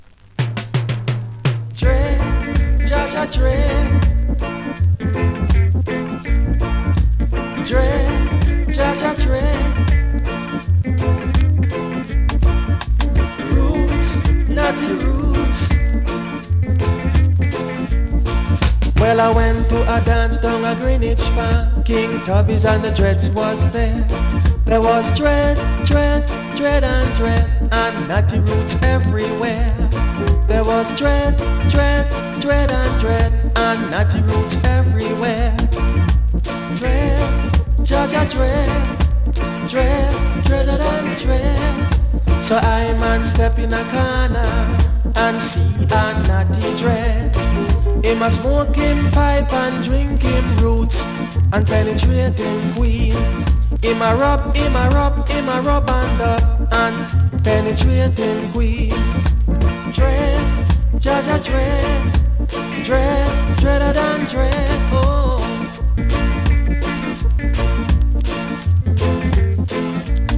中盤で数回ノイズ、他良好
スリキズ、ノイズかなり少なめの